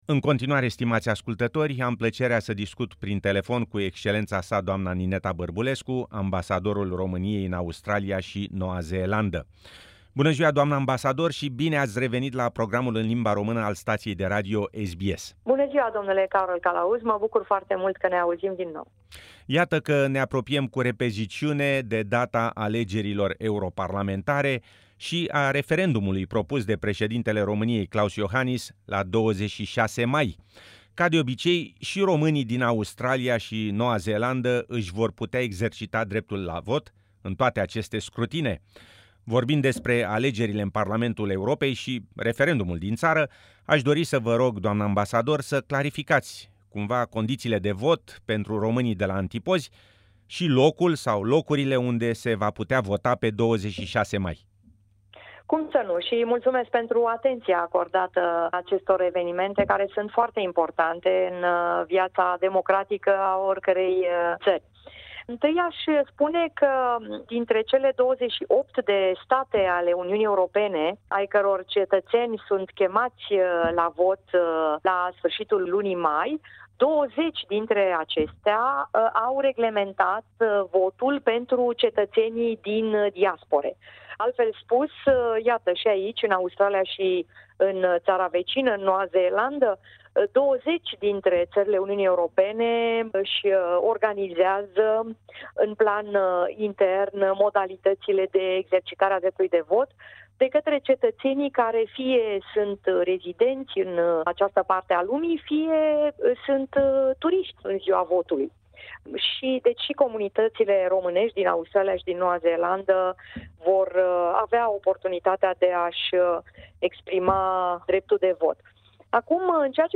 Interview with Her Excellency Nineta Barbulescu, Romanian Ambassador in Australia and New Zealand